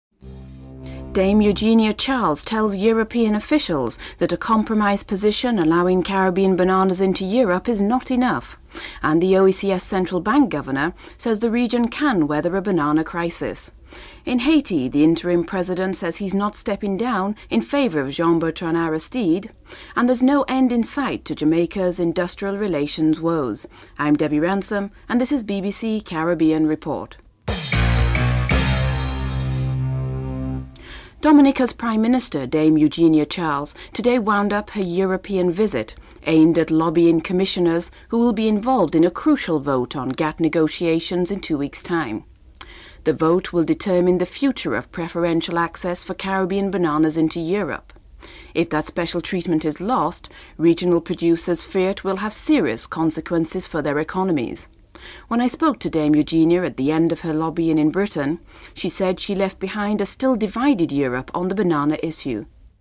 1. Headlines (00:00-00:29)
Ali Bacher of the United Cricket Board of South Africa speaks of the significance of such a visit (13:40-14:45)